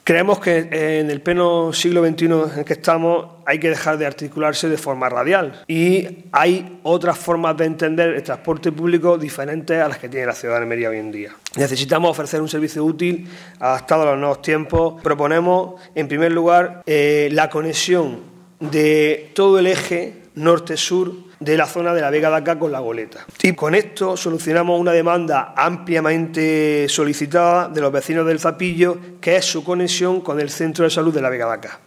Rueda de prensa ofrecida por el concejal del PSOE en el Ayuntamiento de Almería, Indalecio Gutiérrez